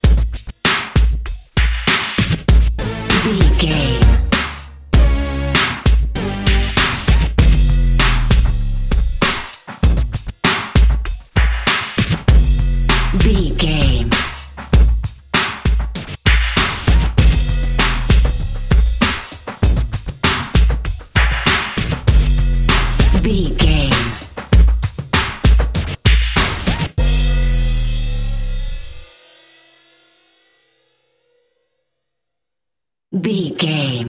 Ionian/Major
drum machine
synthesiser
funky
hard hitting